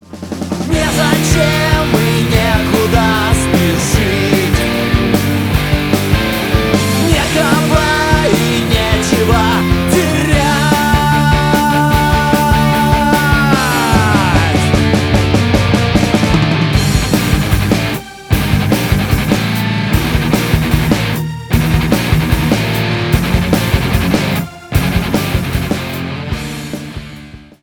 Рок Металл